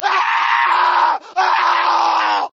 scream3.ogg